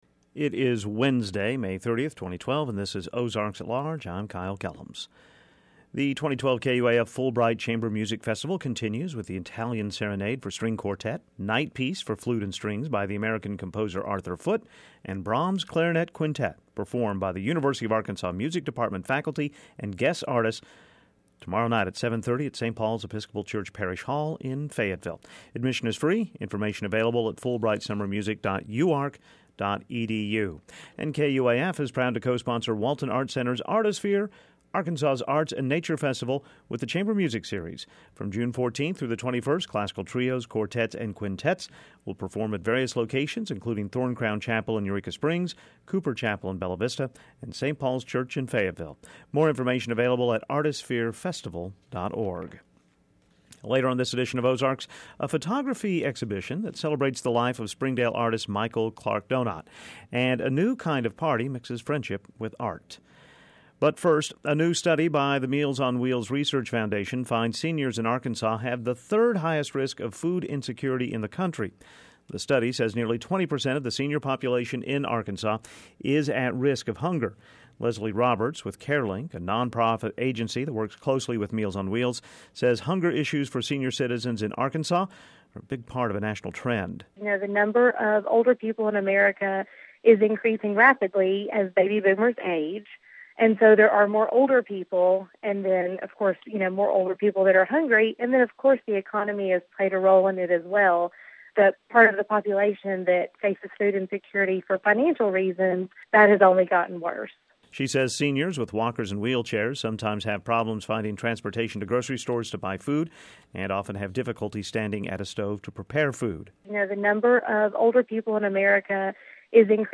a recording of crows